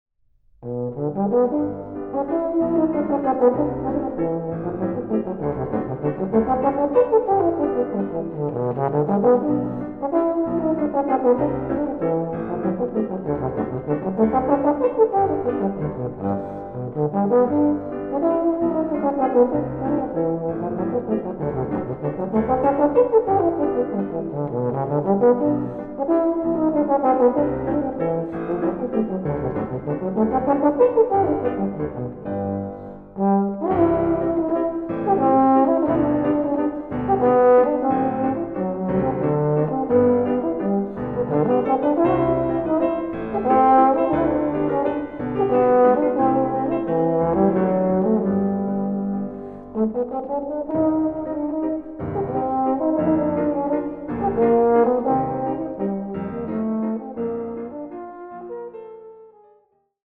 Aufnahme: Mendelssohn-Saal, Gewandhaus Leipzig, 2025
Version for Euphonium and Piano